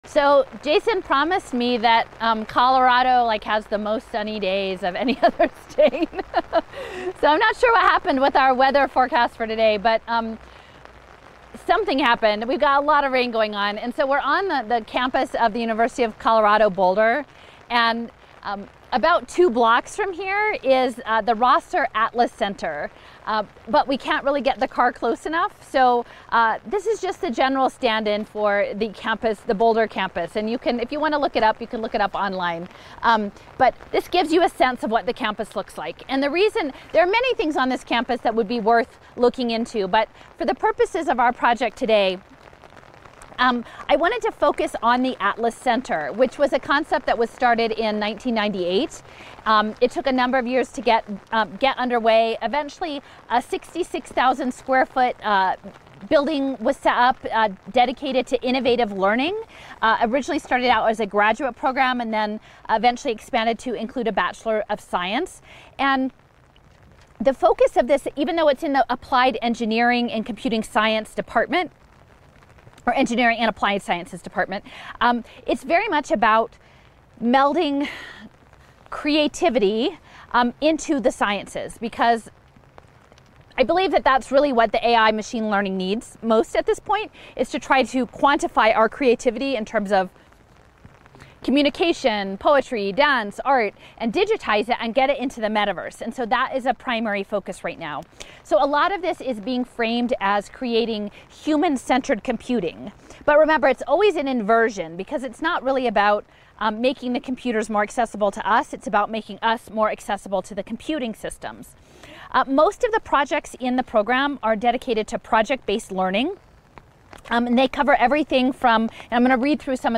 Site visit (near) the Atlas Building on the University of Colorado, Boulder Campus, Colorado.